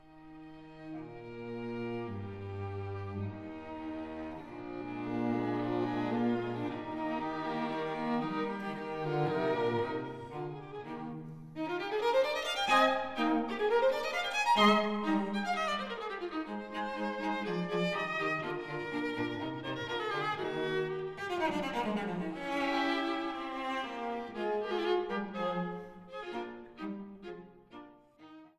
für Violine, Viola und Violoncello: Allegro